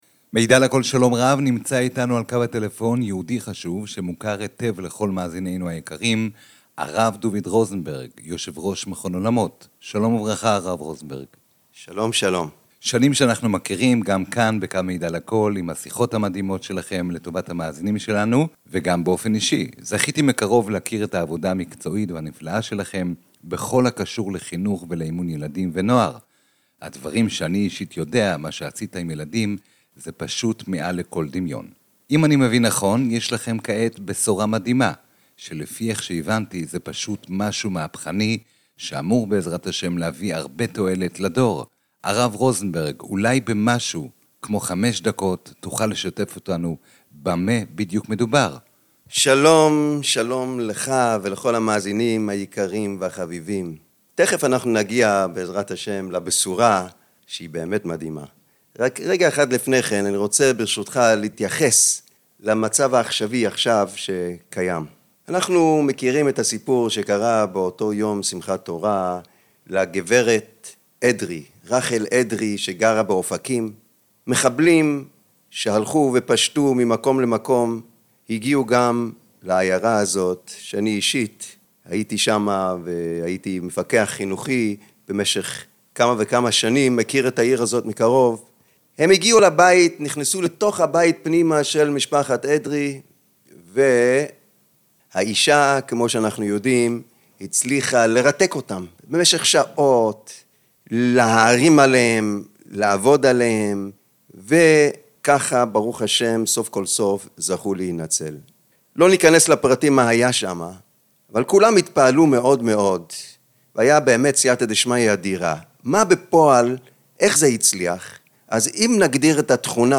ראיון_שיטת_מרגוע_במידע_לכל.mp3